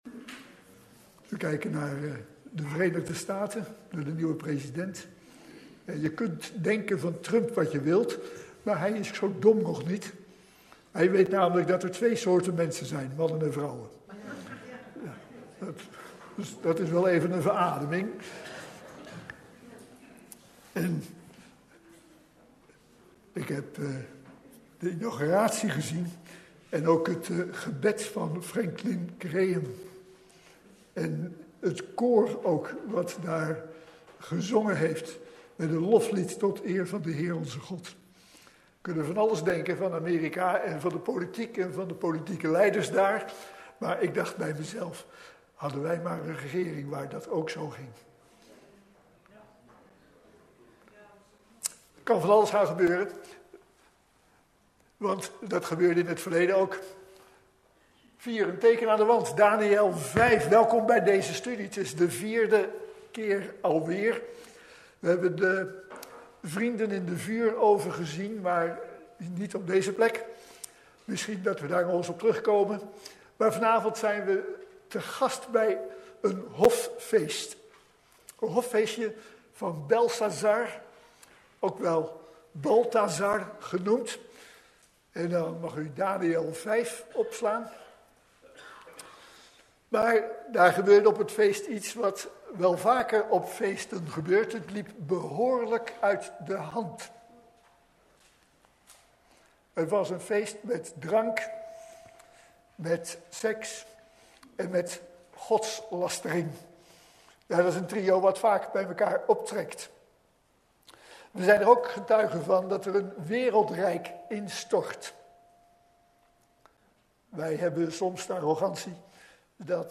AfspelenDeze preek is onderdeel van de serie:"De profeet Daniel"DownloadAudiobestand (MP3)